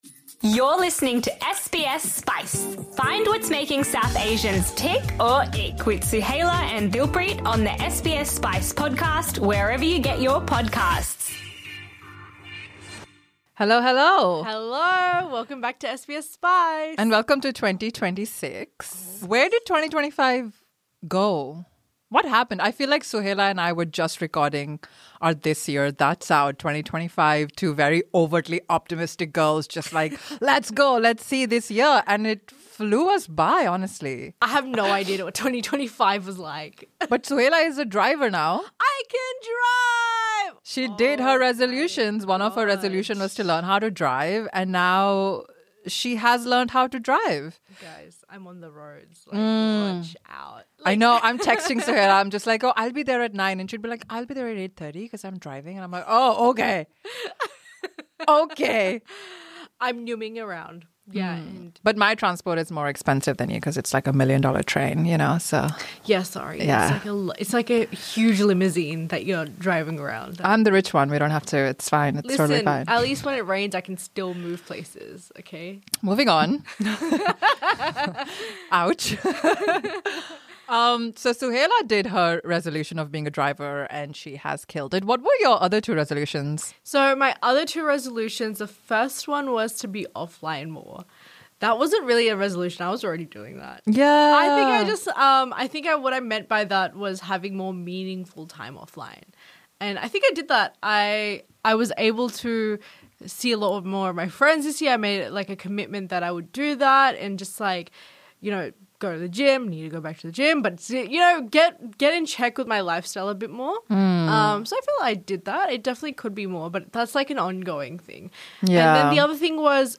Just two women reflecting on the year that was, slowing things down, paying attention and figuring it out in real time.